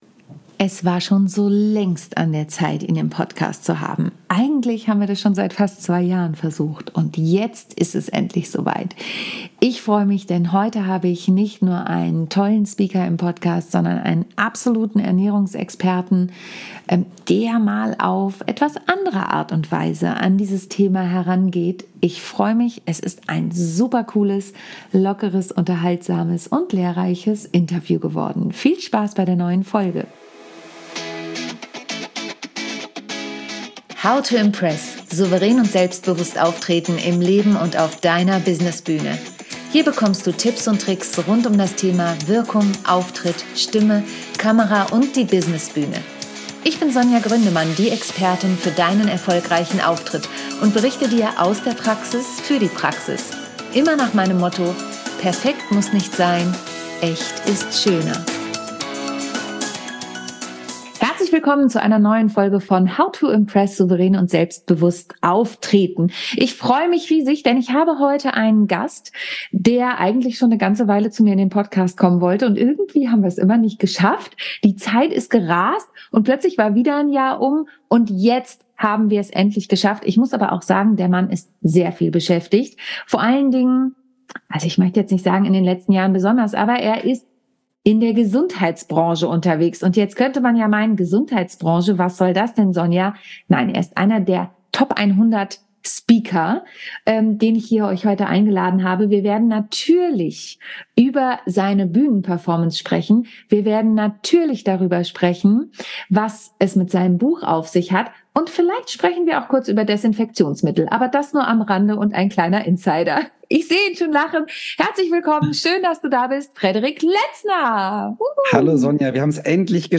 Hach ja, was für ein schönes Interview.
Er spricht mir aus dem Herzen und wir haben viel gelacht.